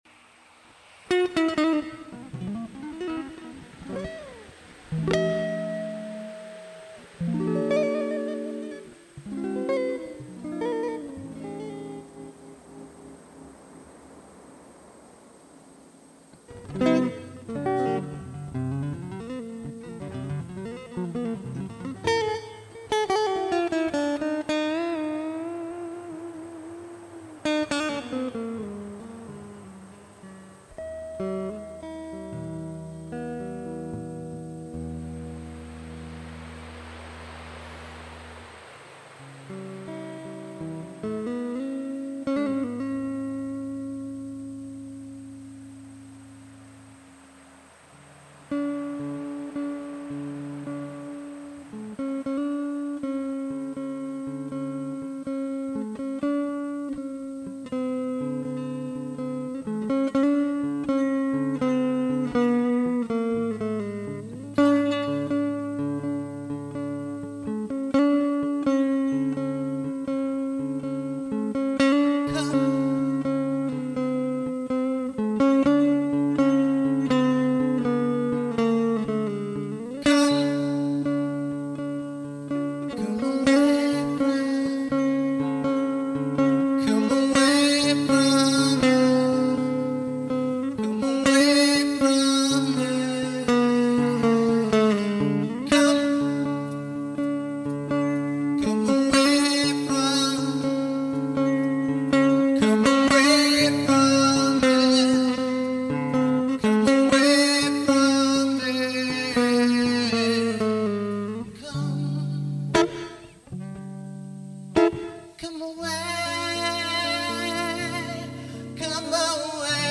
All Instruments & Vocals
Turntablism & Scratches
Raps
Vocals